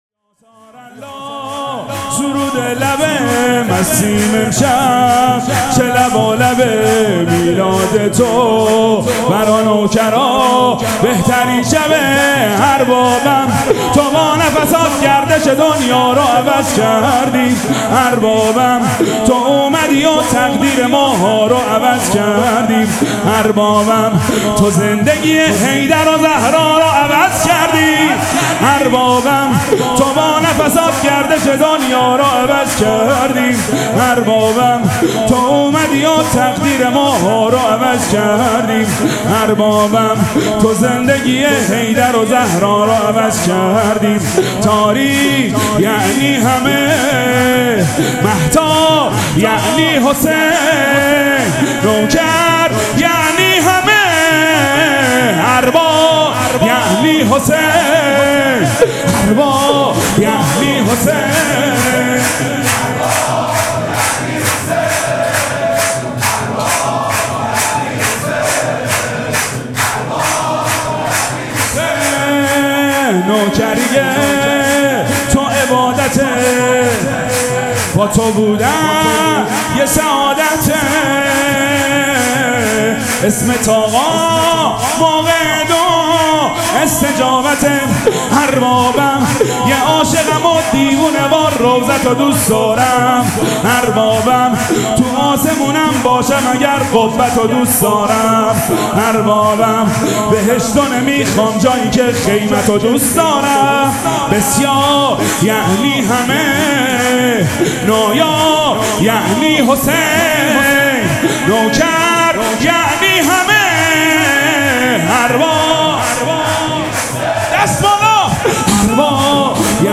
مراسم شب اول ولادت سرداران کربلا
حسینیه ریحانة‌الحسین (س)
شور
مداح